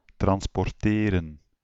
Ääntäminen
France: IPA: [tʁɑ̃s.pɔʁ.te]